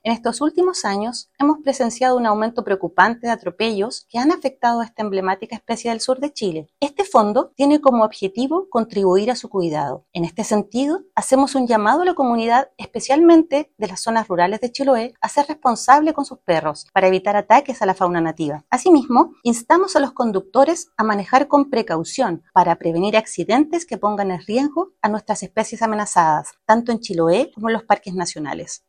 Al respecto, la seremi de Medio Ambiente, Alejandra de la Fuente, expresó su preocupación por el aumento de muertes de este mamífero.
alejandra-de-la-fuente-seremi-medio-ambiente-cuna.mp3